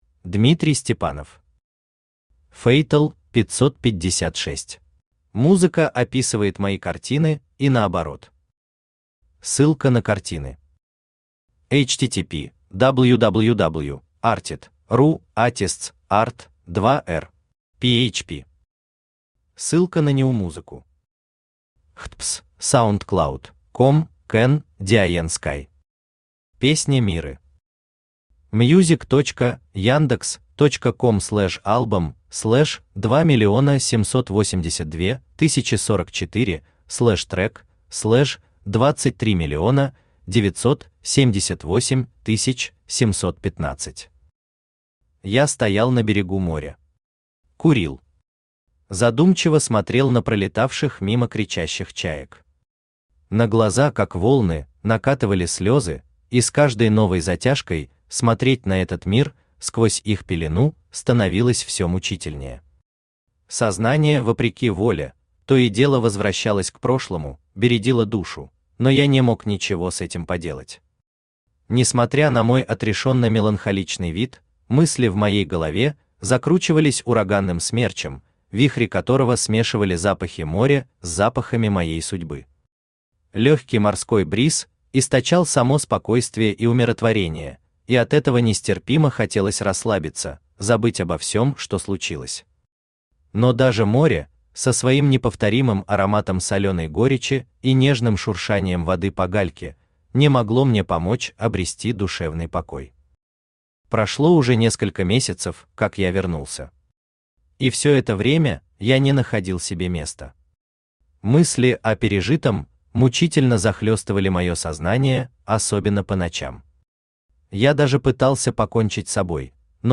Аудиокнига FATAL-556 | Библиотека аудиокниг
Aудиокнига FATAL-556 Автор Дмитрий Николаевич Степанов Читает аудиокнигу Авточтец ЛитРес.